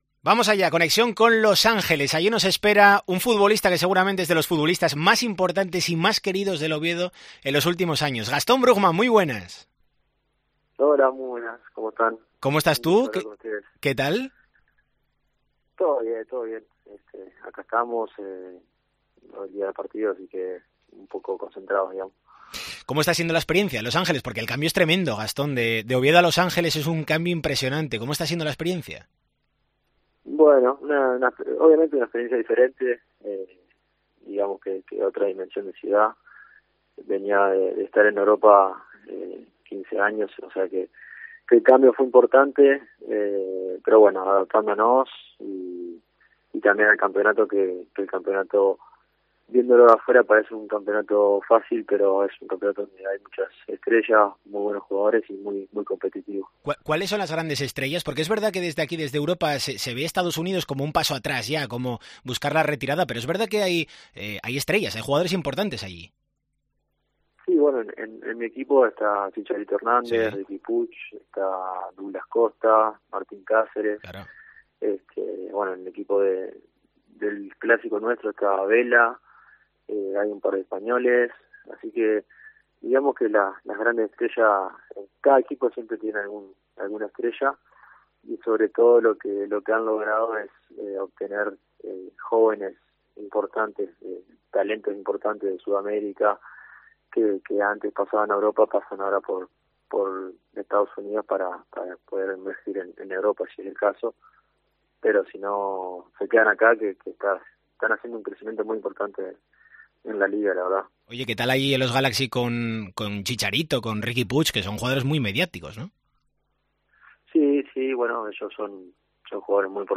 El jugador de Los Ángeles Galaxy concede a COPE su primera entrevista tras su salida del Real Oviedo. Abre la puerta a un regreso al Carlos Tartiere.
Desde la costa oeste de los Estados Unidos, Brugman atiende la llamada de Deportes COPE Asturias en una extensa entrevista donde repasa pasado, presente y futuro.